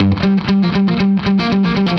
Index of /musicradar/80s-heat-samples/120bpm
AM_HeroGuitar_120-G01.wav